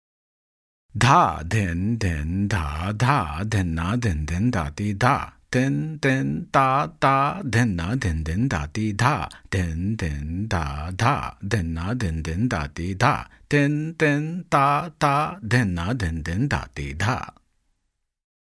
Spoken 2 times: